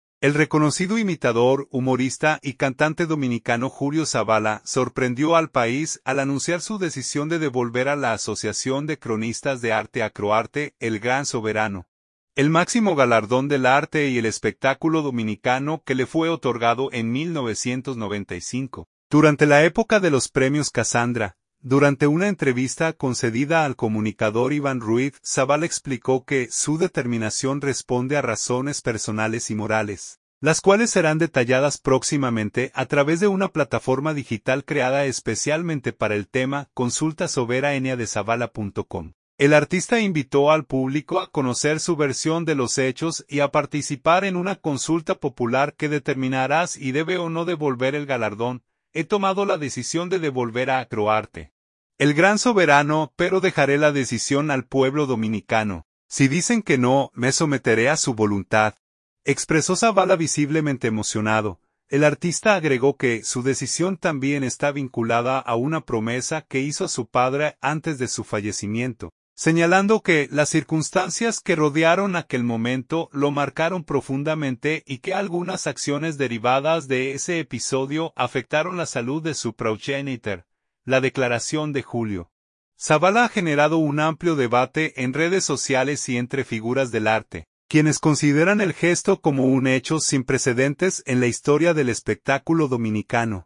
“He tomado la decisión de devolver a Acroarte el Gran Soberano… Pero dejaré la decisión al pueblo dominicano. Si dicen que no, me someteré a su voluntad”, expresó Sabala visiblemente emocionado.